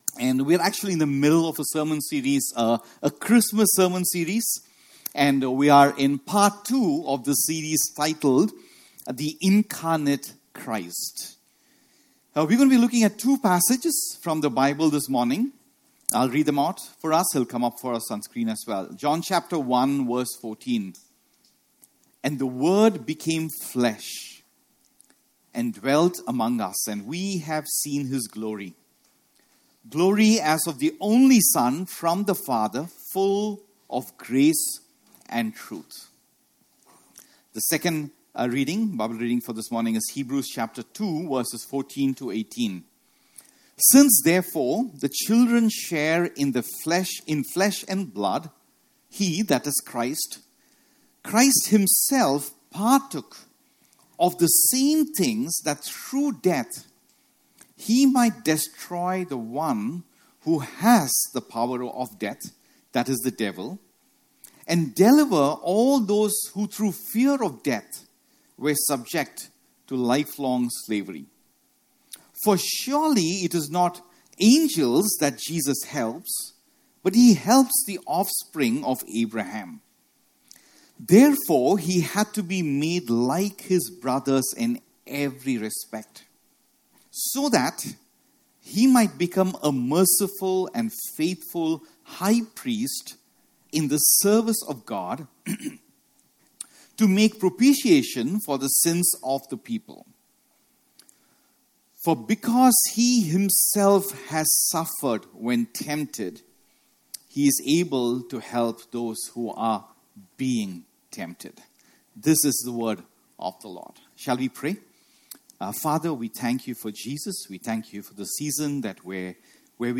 Sermons // Gospel-Centered // Life-Changing // Motivating //